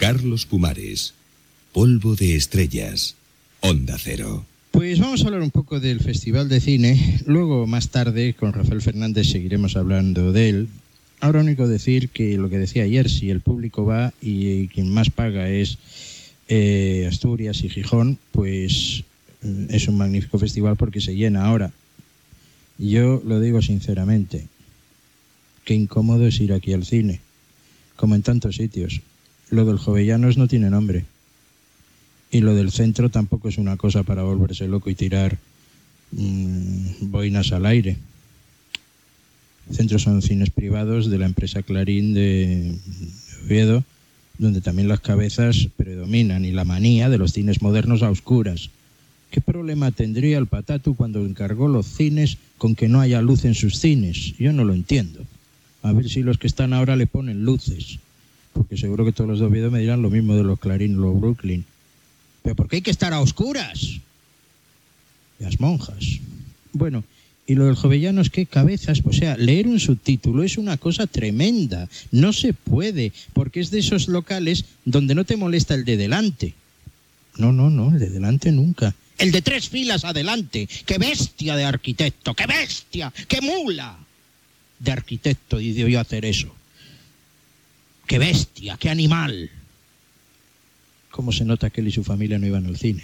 Indicatiu del programa, comentari crític sobre les sales de cinema d'Oviedo.